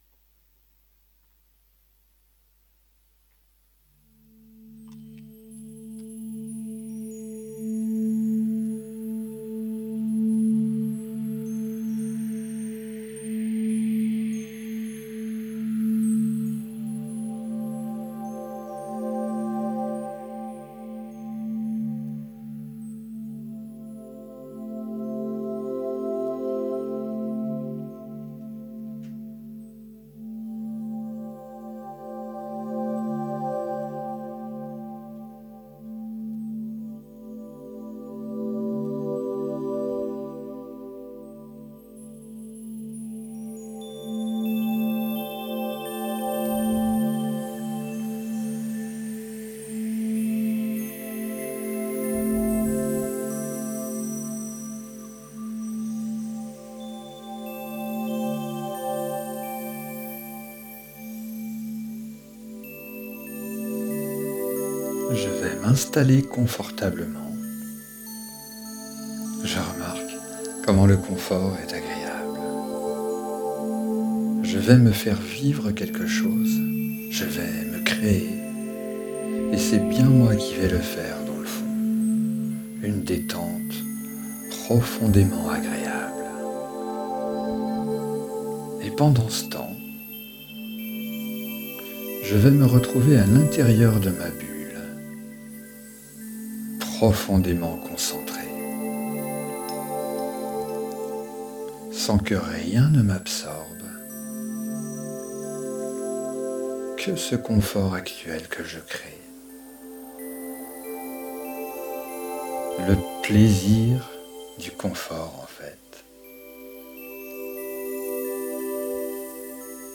Cet enregistrement est une aide, un soutien, mais ne remplace pas une réelle séance d'hypnothérapie.